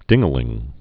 (dĭngə-lĭng)